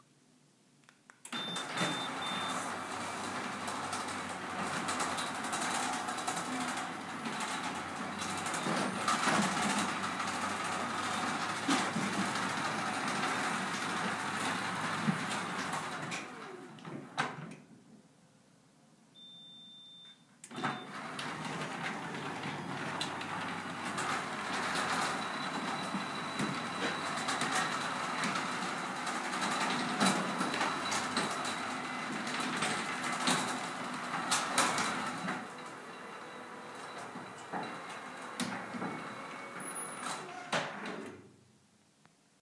J'envoie le son de suite du cycle.
Ci joint le fichier audio... ouverture et fermeture d'une porte Hormann 5.5m de large sur 2.2 de haut avec joint thermoframe.